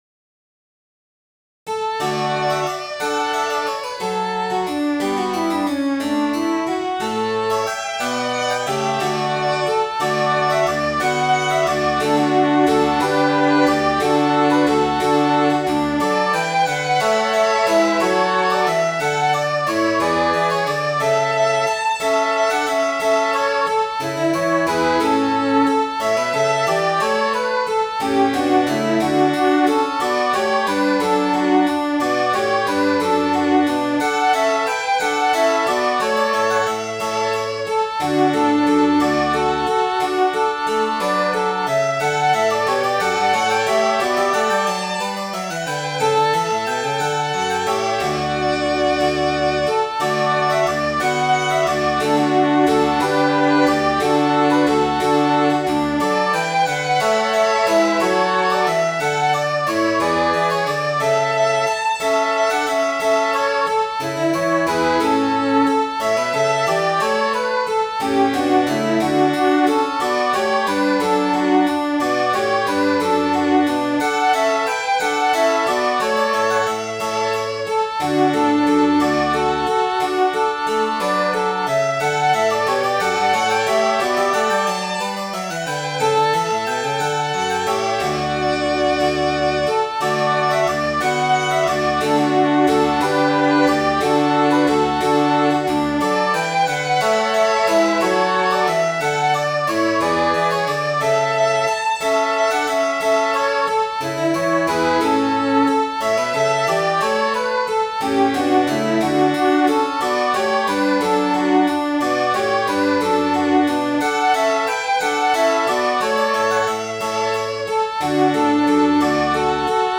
Midi File, Lyrics and Information to Come Here, Fellow Servant